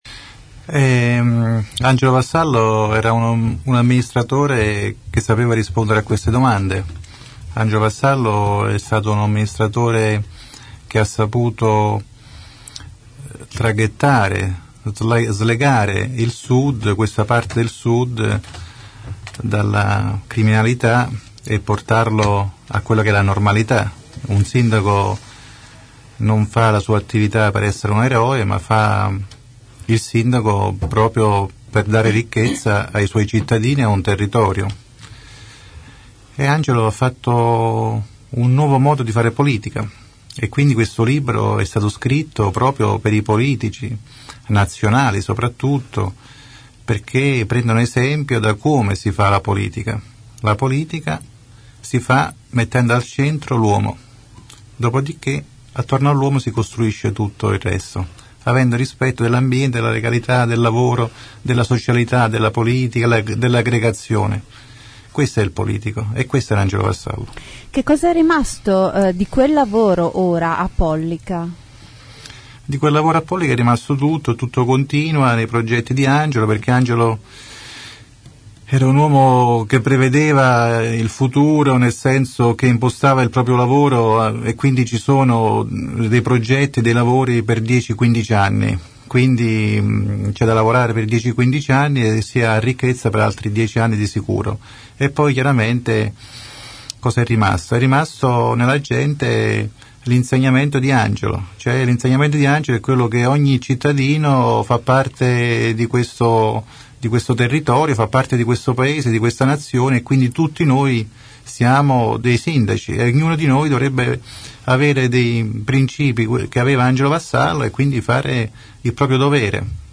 Ascolta la diretta radiofonica.